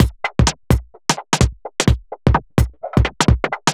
Index of /musicradar/uk-garage-samples/128bpm Lines n Loops/Beats
GA_BeatEnvC128-04.wav